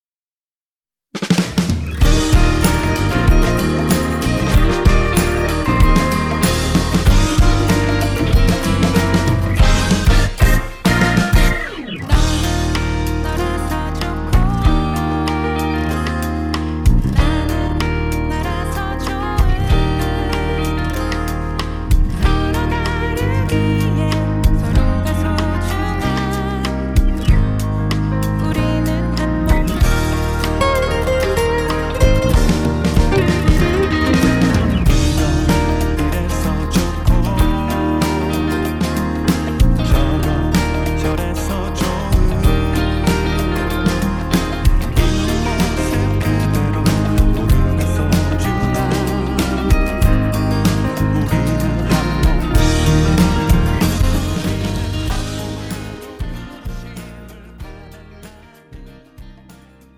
음정 -1키 3:35
장르 가요 구분 Voice Cut
가사 목소리 10프로 포함된 음원입니다